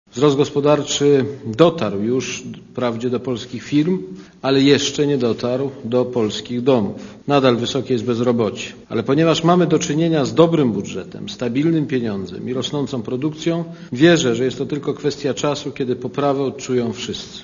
Posłuchaj prezydenta Kwaśniewskiego